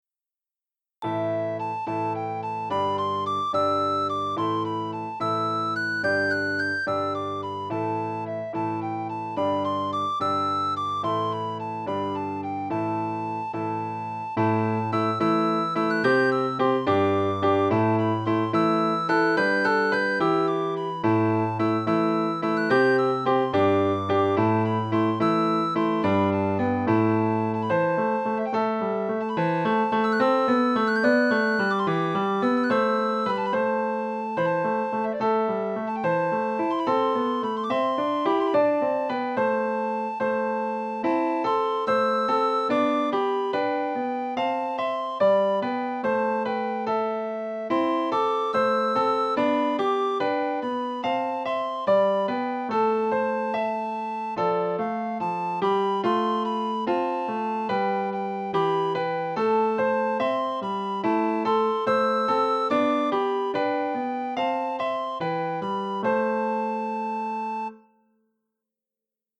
für Sopranblockflöte (Violine, Flöte) und Klavier